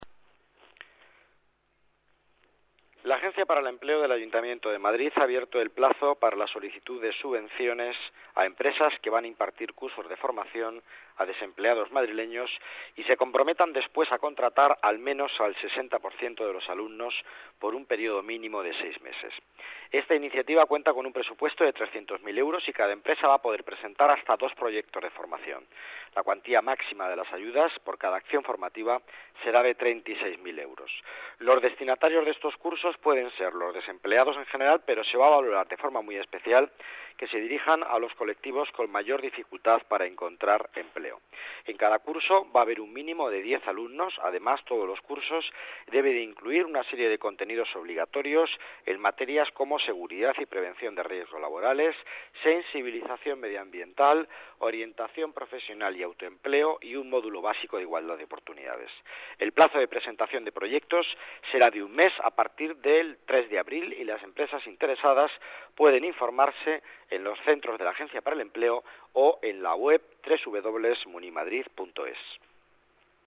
Nueva ventana:Declaraciones delegado Economía y Empleo, Miguel Ángel Villanueva: subvenciones formación desempleados